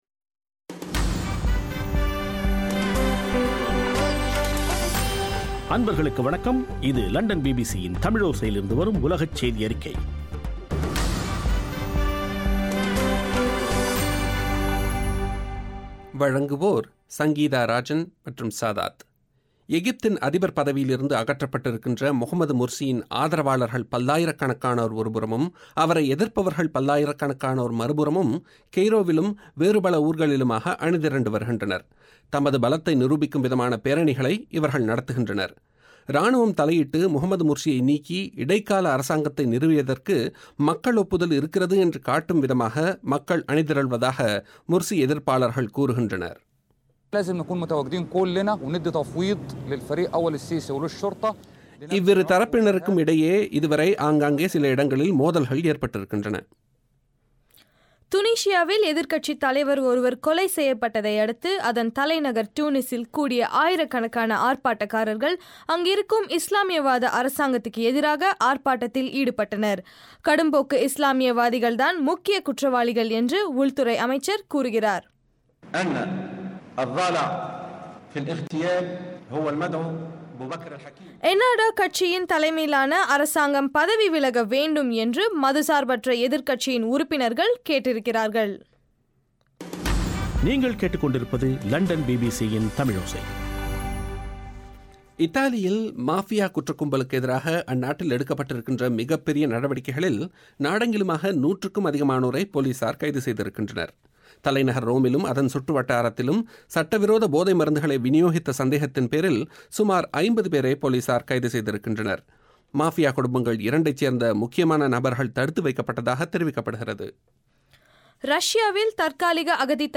பிபிசி தமிழோசையின் சர்வதேச செய்தியறிக்கை, 26-07-13